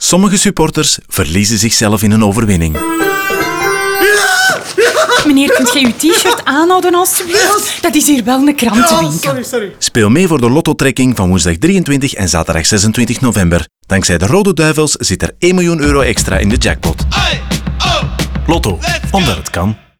Ook in radio leeft de campagne waar typische stadiontaferelen hun weg hebben gevonden naar… de krantenwinkel.
Radiospot 2.wav